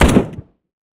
m4a1fire_stereo.wav